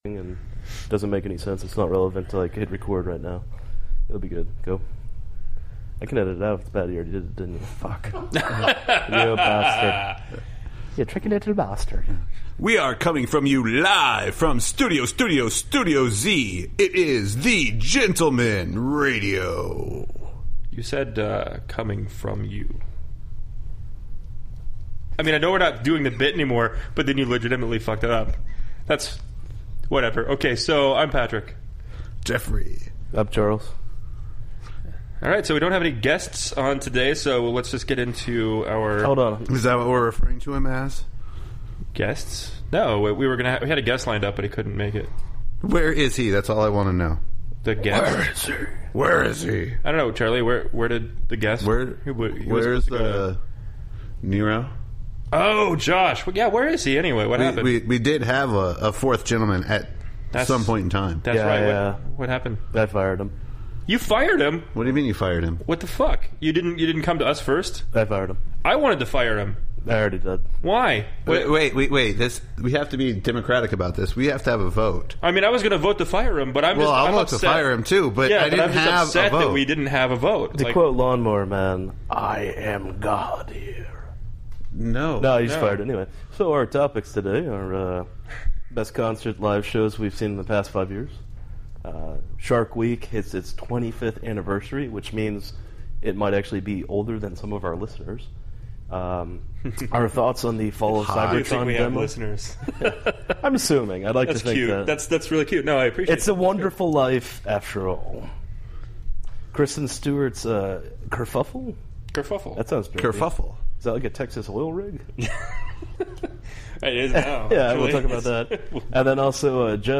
It is a very special episode of the Gentlemen Radio this week as we have no guests and it is just the original three Gentlemen.